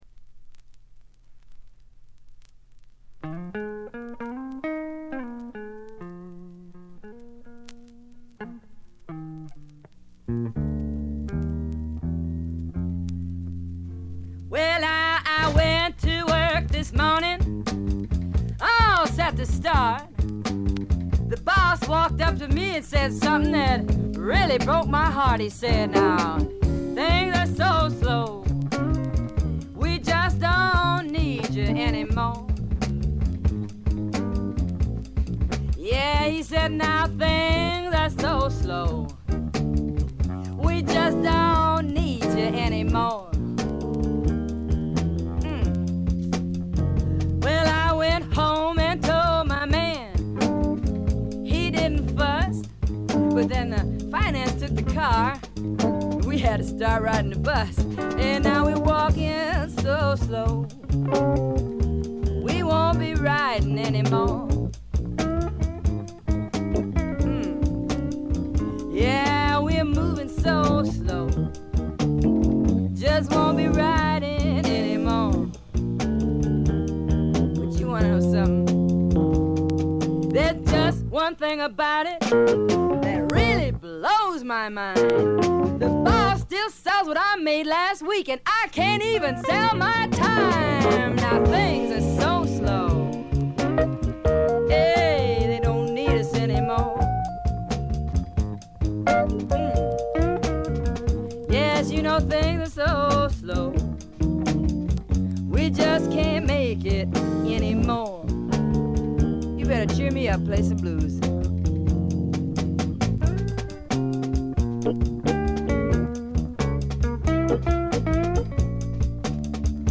存在感抜群の強靭なアルト・ヴォイスが彼女の最大の武器でしょう。
試聴曲は現品からの取り込み音源です。
Vocals, Guitar